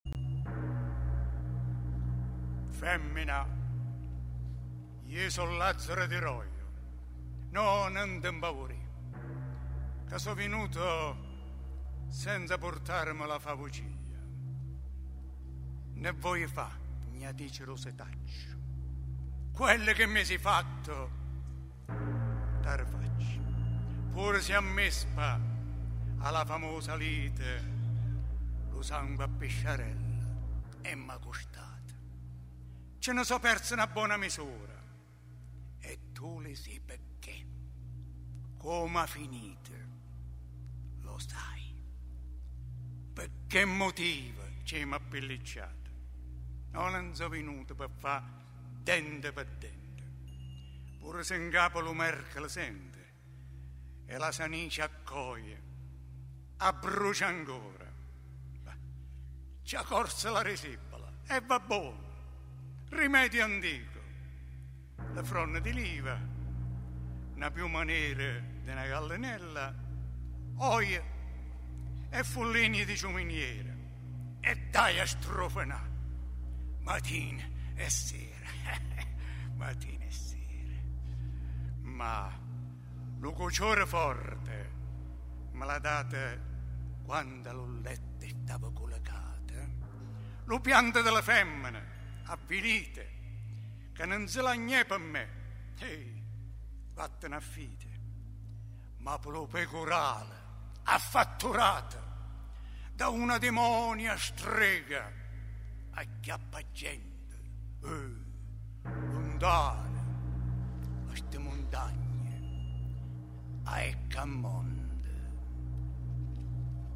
Pagine scelte dall’opera di Gabriele D’Annunzio Tradotte in versi dialettali abruzzesi